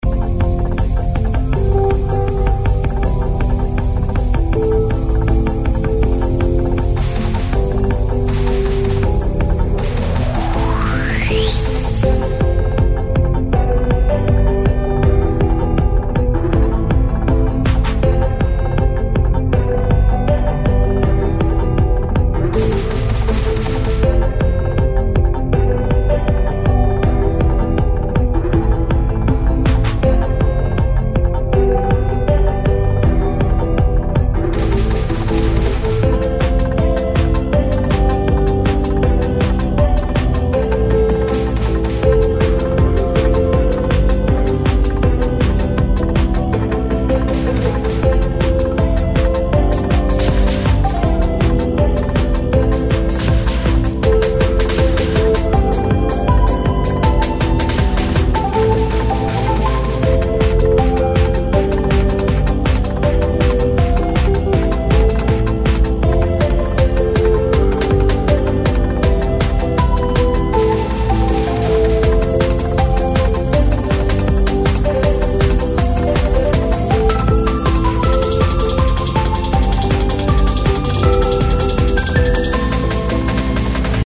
それぞれ１ループの音源です♪